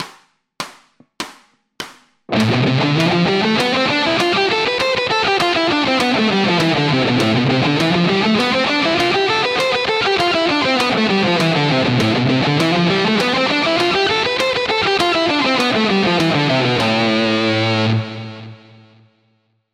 Следующая гамма – ля минор.
Аудио (100 УВМ)